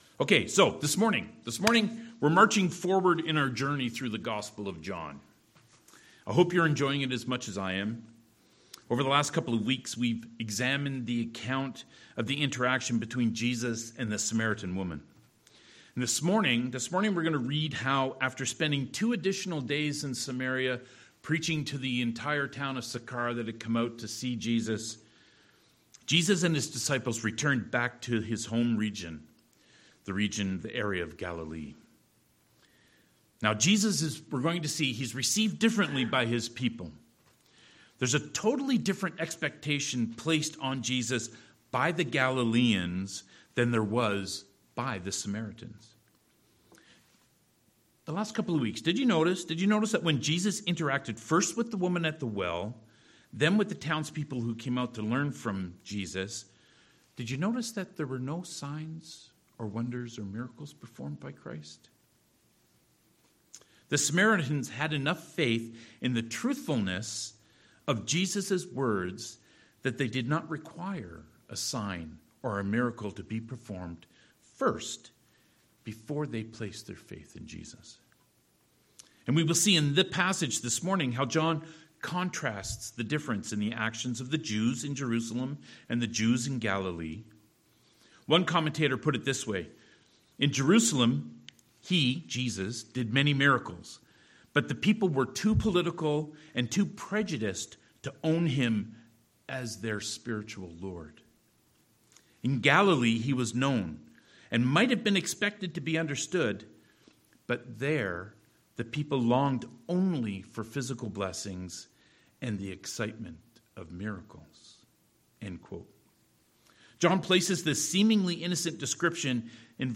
43-54 Service Type: Sermons « The Ripple Effect Does Faith Require Action?